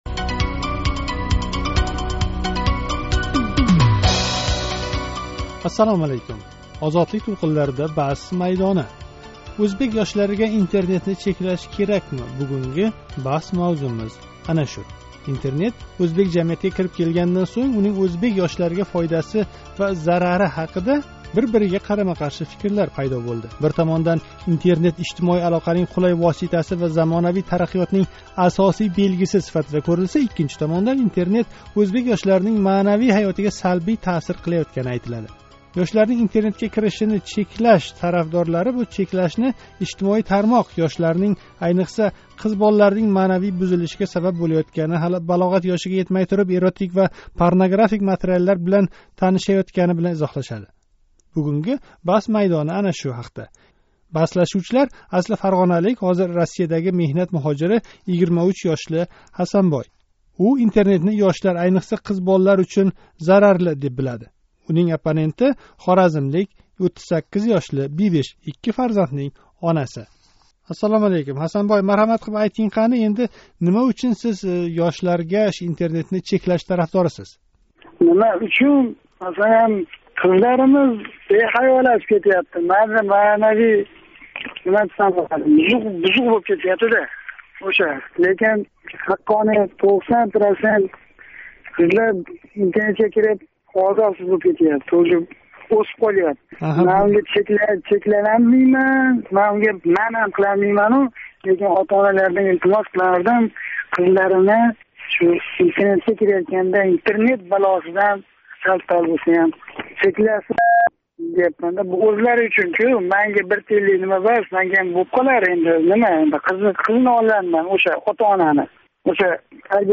Озодликнинг Баҳс майдонида ўзбек ёшларининг Интернетдан фойдаланишини чеклаш тарафдорлари ва қаршилари баҳслашади.